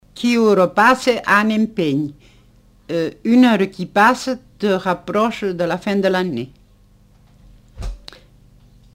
Lieu : Montauban-de-Luchon
Genre : forme brève
Type de voix : voix de femme
Production du son : récité
Classification : proverbe-dicton